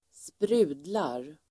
Uttal: [²spr'u:dlar]
sprudlar.mp3